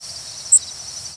Yellow Warbler Dendroica petechia
Flight call description A trilled "dziit", usually loud and somewhat sweet. Variable in pitch.
Fig.1. New Jersey September 6, 1997 (MO).
Bird in flight.